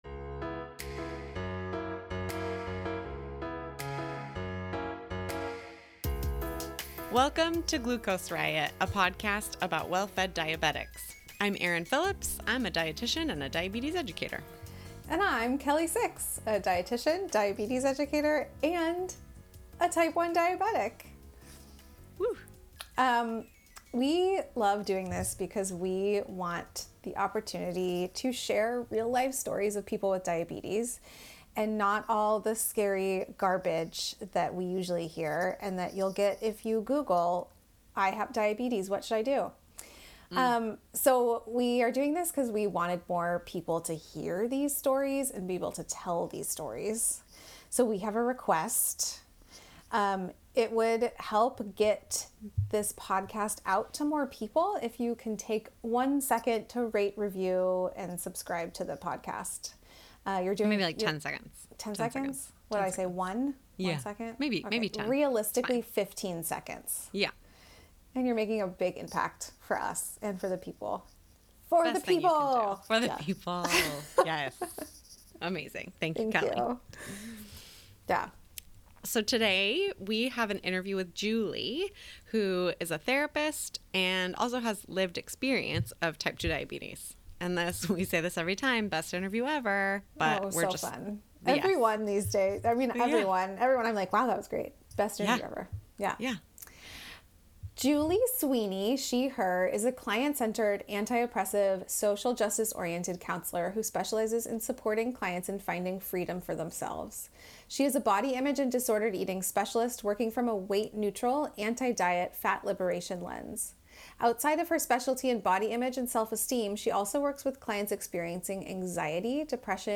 A couple of notes: We recorded this back in DECEMBER, and then life got lifey! Better late than never, because we'd hate for you to miss out on this great interview!